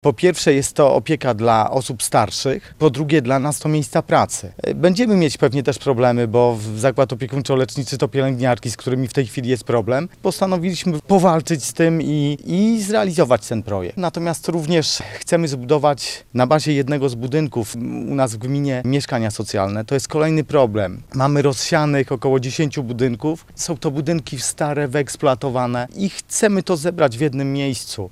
– Chcemy utworzenia Zakładu Opiekuńczo-Leczniczego w pobliskim Curynie. Po pierwsze będzie to miejsce, w którym osoby starsze znajdą opiekę, po drugie – mieszkańcy gminy znajdą tam zatrudnienie – mówi wójt gminy, Piotr Dragan.